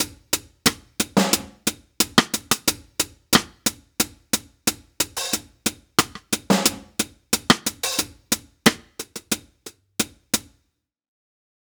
Unison Jazz - 9 - 90bpm - Tops.wav